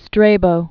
(strābō) 63?